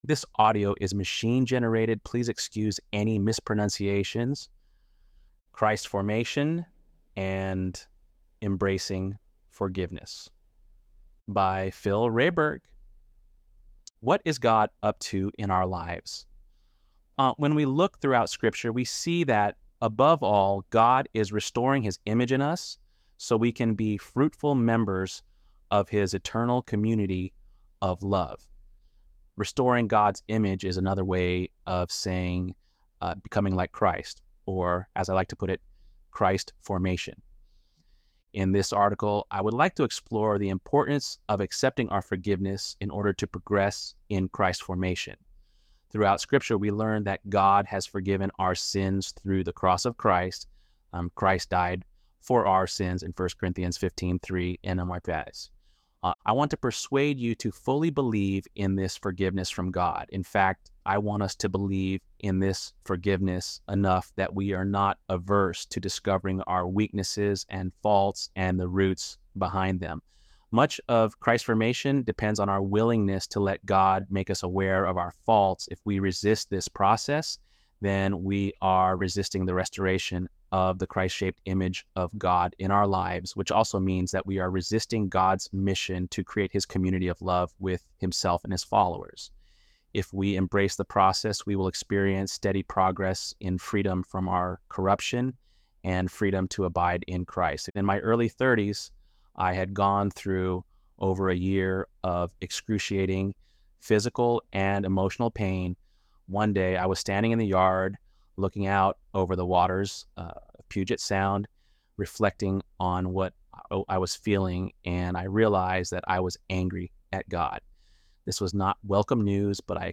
ElevenLabs_8.8_Christ.mp3